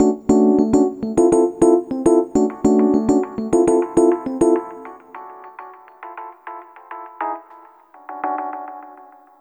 Ala Brzl 1 Fnky Piano-B.wav